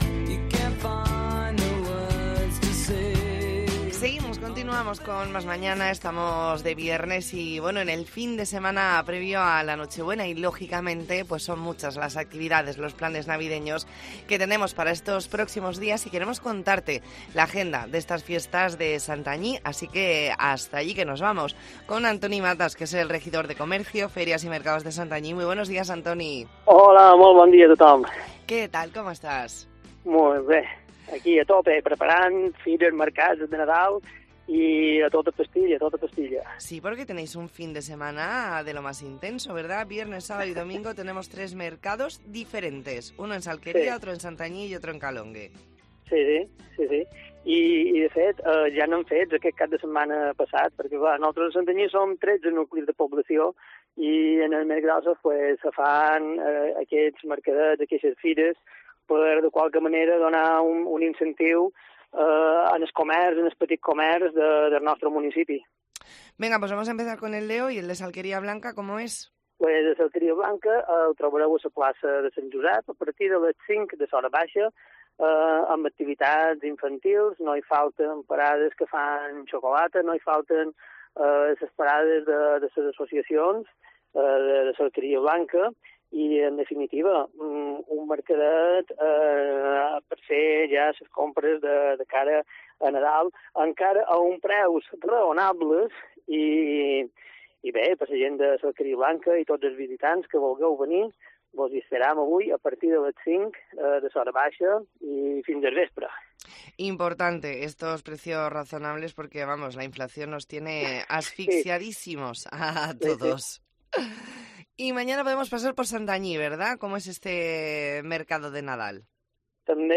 ntrevista en La Mañana en COPE Más Mallorca, viernes 15 de diciembre de 2023.